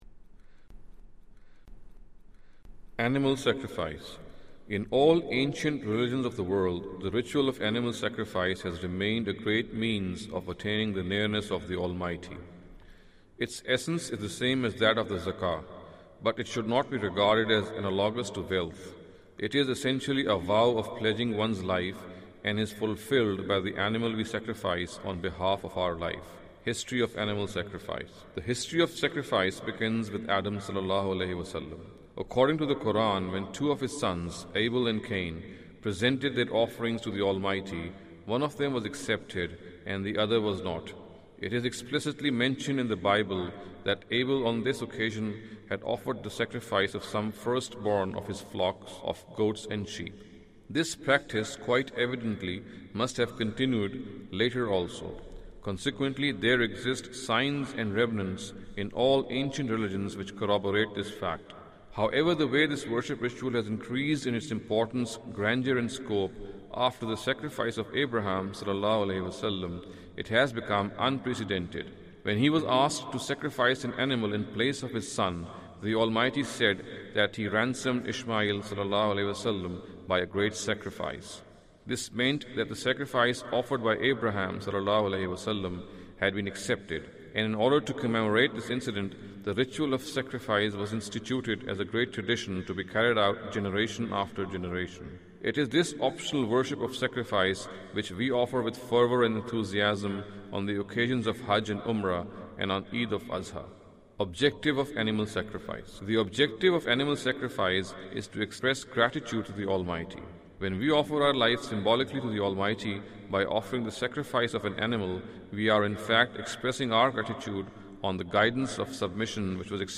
Category: Audio Books / Islam: A Concise Introduction /